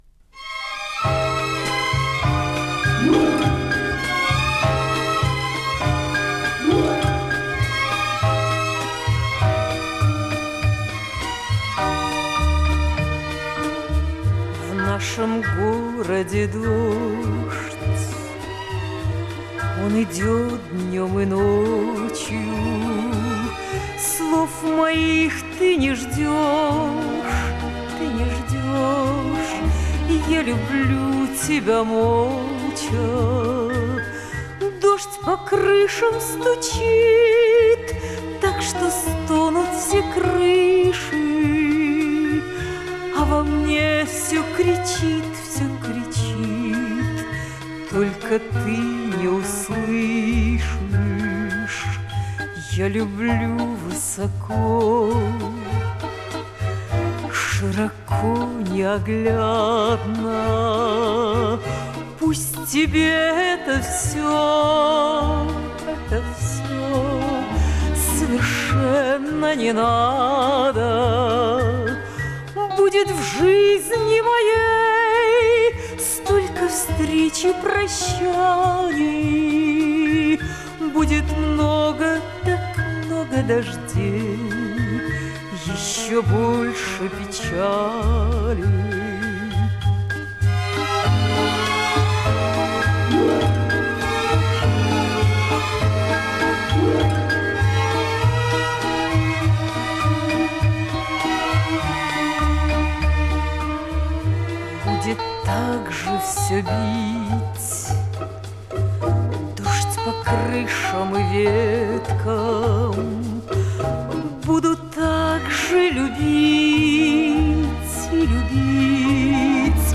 И было на Огоньке.